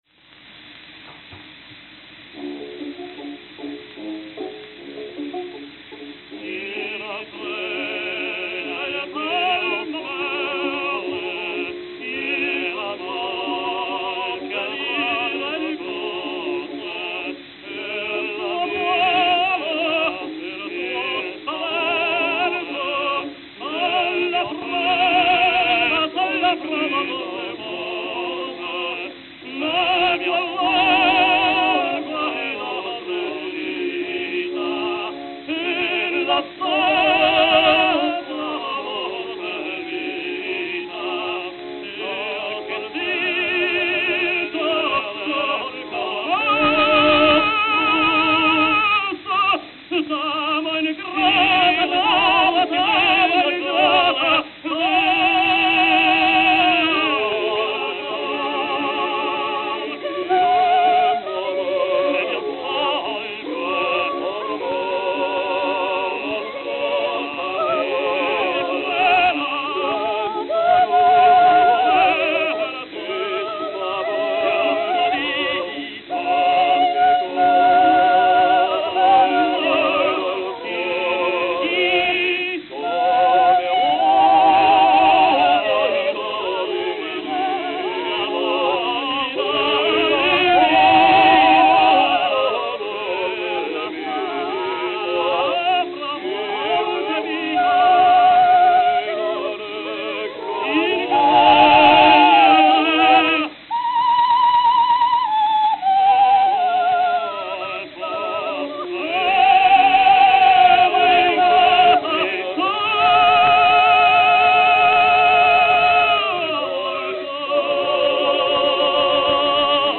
New York, New York
Baton audible before song. Worn towards end.